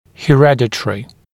[hɪ’redət(ə)rɪ] [he-][хи’рэдэт(э)ри] [хэ-]наследственный